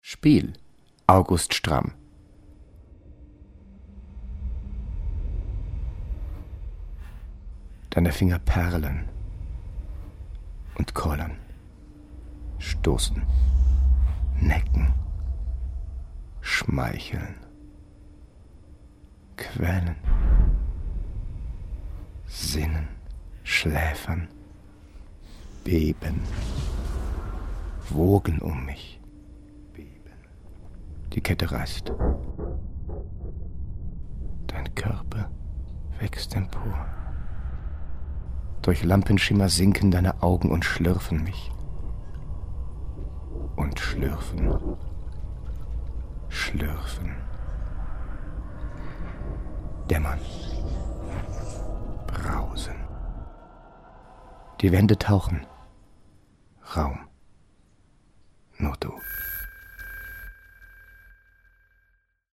Lyrik
Spiel – Gedicht von August Stramm (1874-1915)